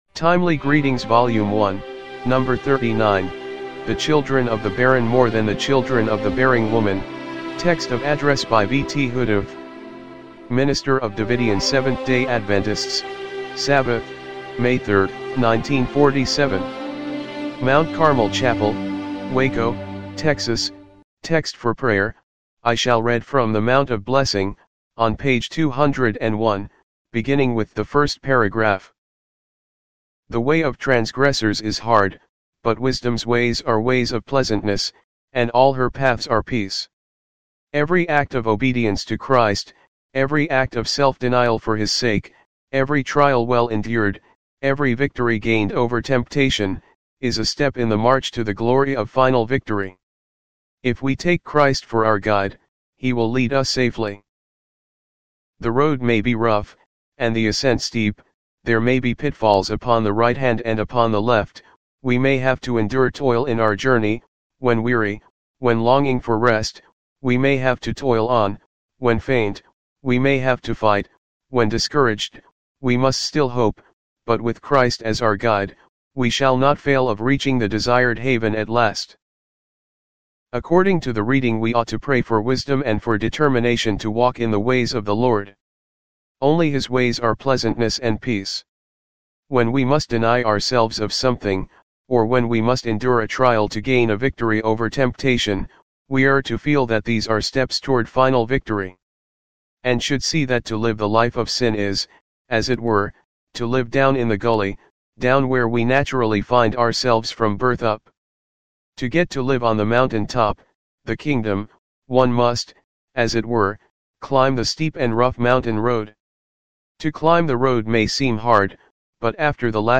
timely-greetings-volume-1-no.-39-mono-mp3.mp3